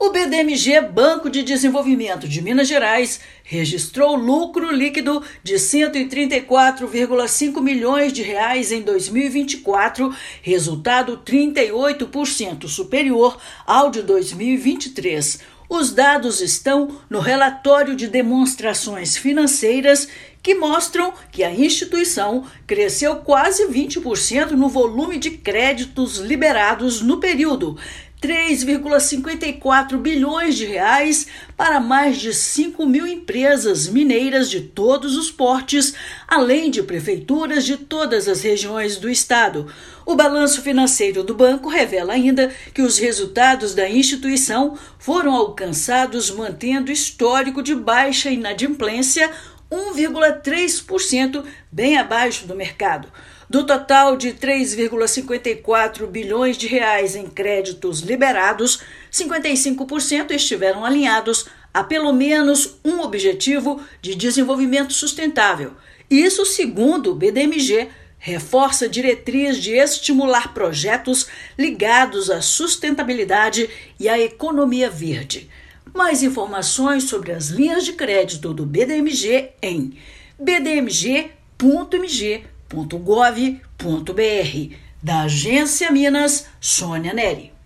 Relatório de demonstrações financeiras mostra a presença da instituição em 91% do estado, com operações em 775 dos 853 municípios. Ouça matéria de rádio.